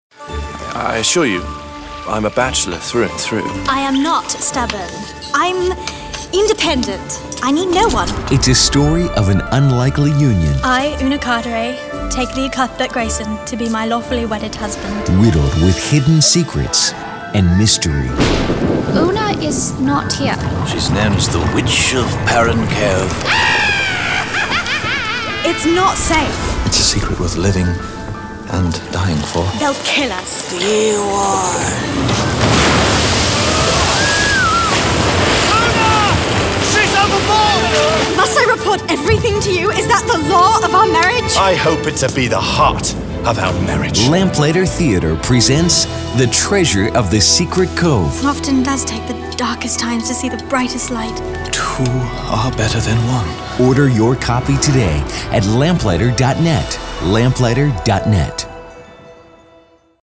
Treasure of the Secret Cove, The – Dramatic Audio CD